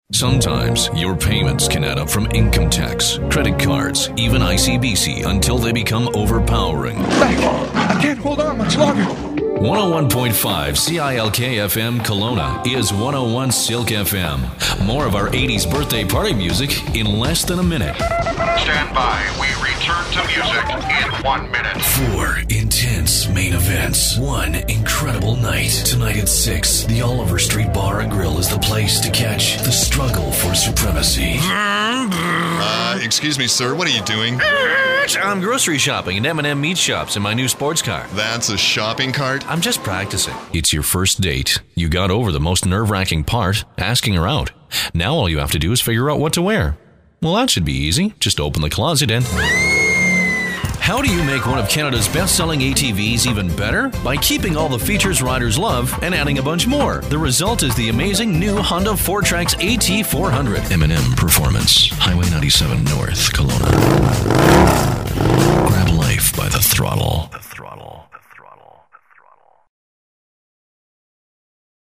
Male
My natural voice is easily described as deep, authoritative or commanding.
Radio Commercials
Radio And Tv Voiceovers
1019Commercial_Demo.mp3